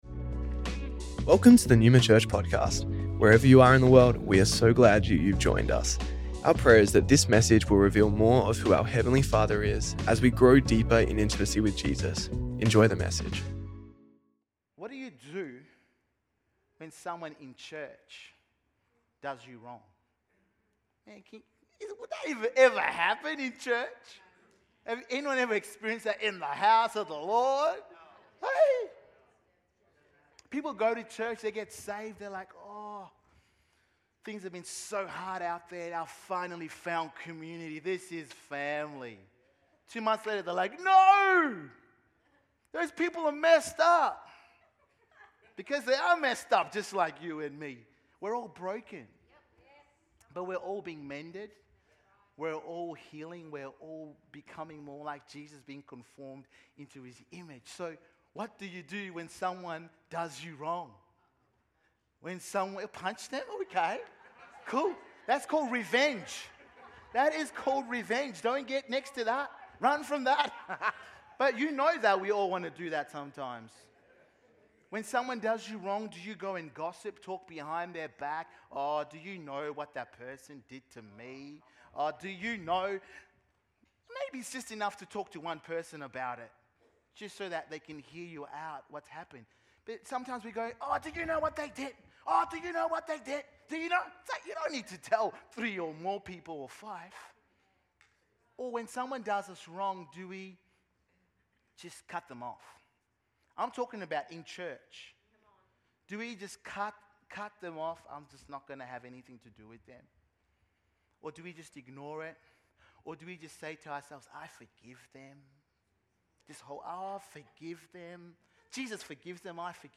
Neuma Church Melbourne South Originally recorded at the 10AM Service on Sunday 13th July 2025